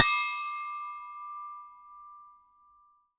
bell_small_ringing_01.wav